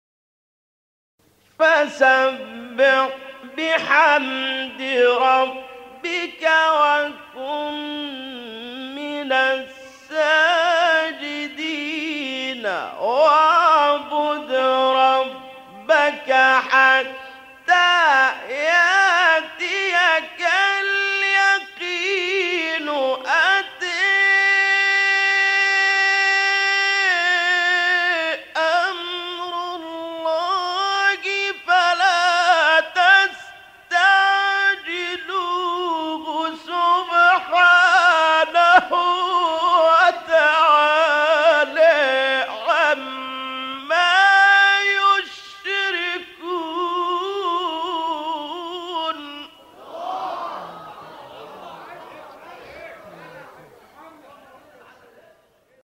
گروه شبکه اجتماعی: مقاطعی صوتی از تلاوت قاریان برجسته مصری ارائه می‌شود.
مقطعی از محمد اللیثی در مقام رست